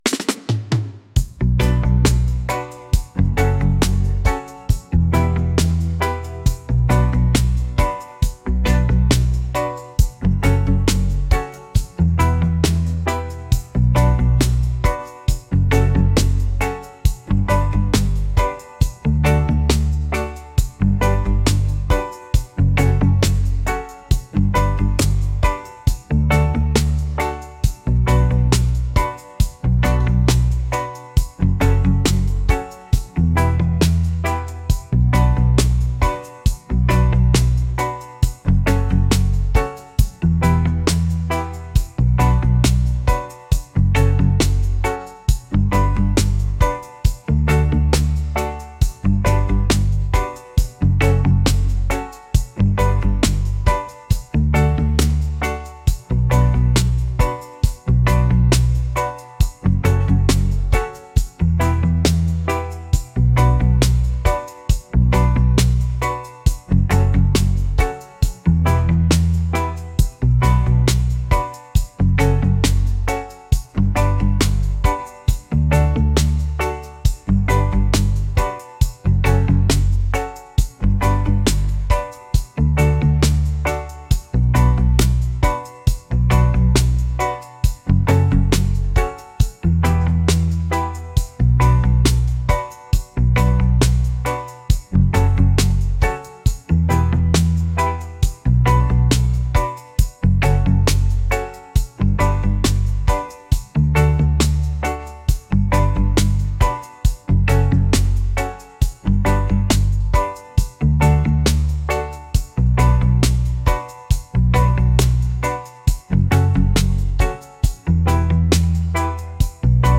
mellow | romantic | reggae